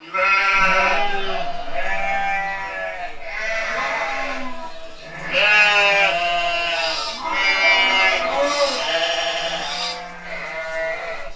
sheeppen.wav